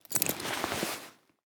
knapsack_open.wav